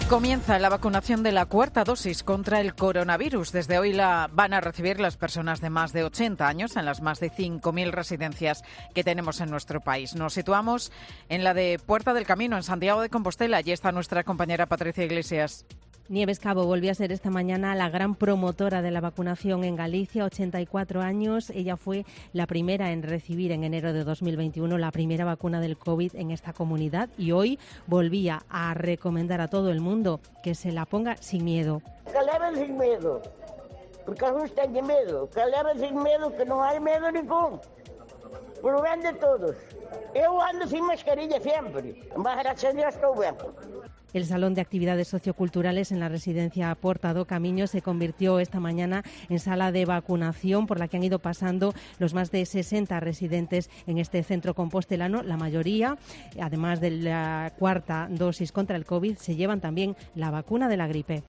En las residencias ha comenzado la vacunación con la cuarta dosis. Crónica COPE Galicia